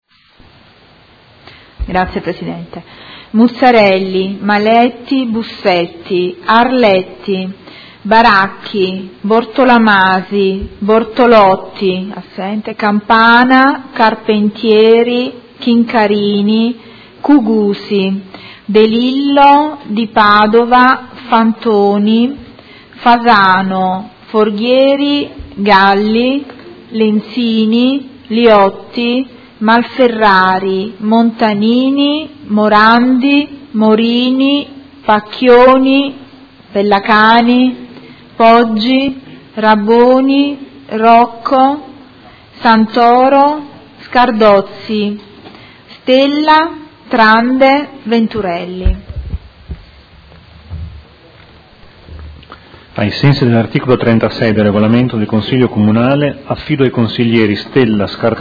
Segretario Generale — Sito Audio Consiglio Comunale
Seduta del 19/01/2017 Appello